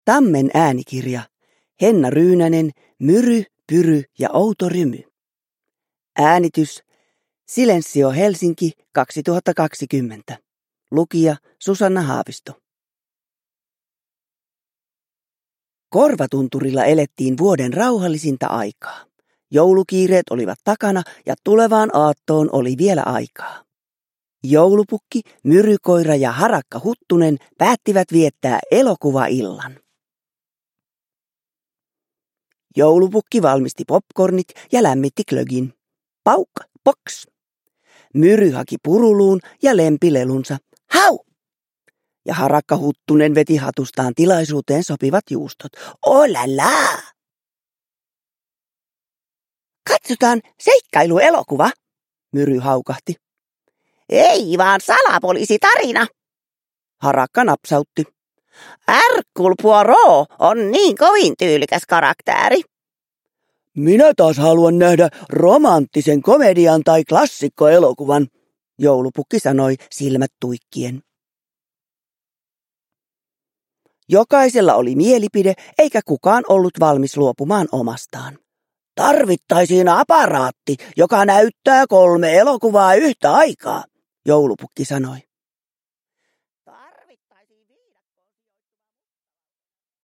Myry, Pyry ja outo rymy – Ljudbok – Laddas ner
Uppläsare: Susanna Haavisto